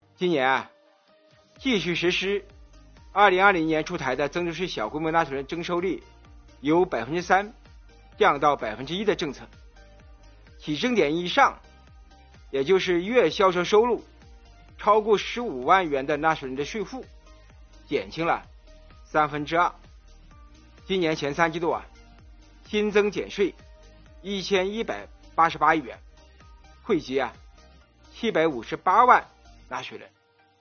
11月5日，国务院新闻办公室举行国务院政策例行吹风会，国家税务总局相关负责人介绍制造业中小微企业缓税政策等有关情况，并答记者问。